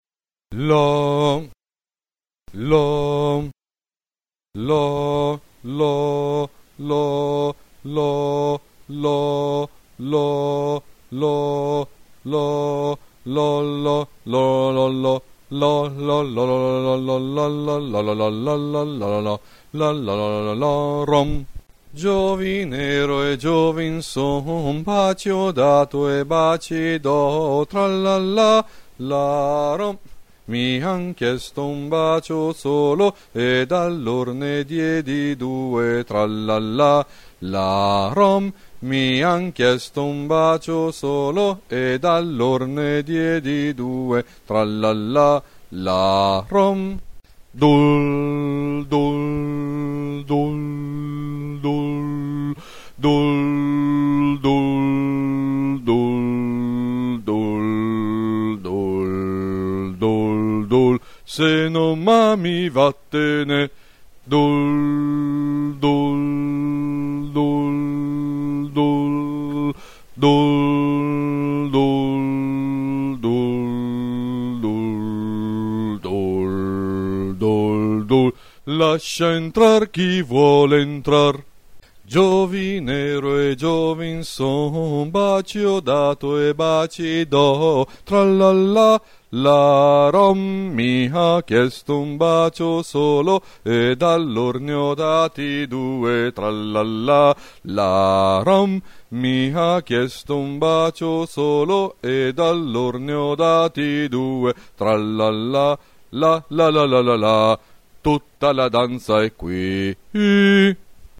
UOMINI